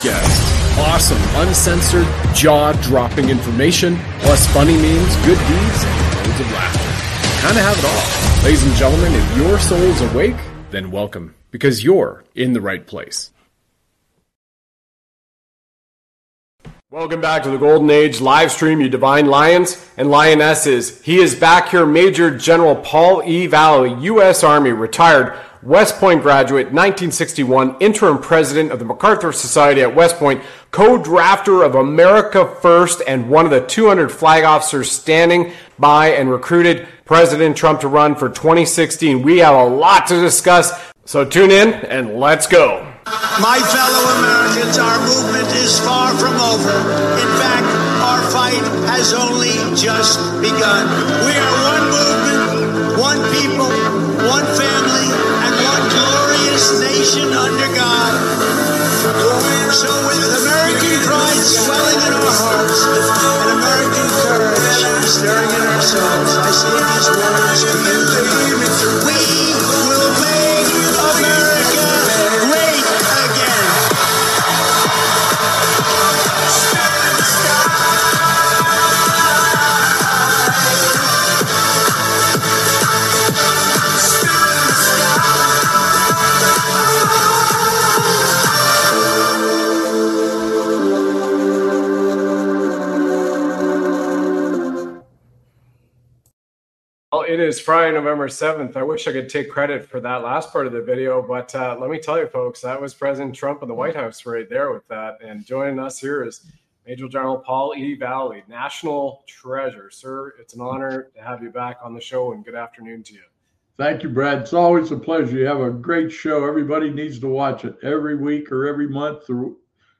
The text is a conversation between a host and Major General Paul E. Valley, a retired US army officer.